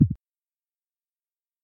Звуки выключения
Кнопка реагирует на отключение